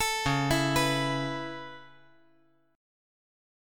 C#m7#5 Chord
Listen to C#m7#5 strummed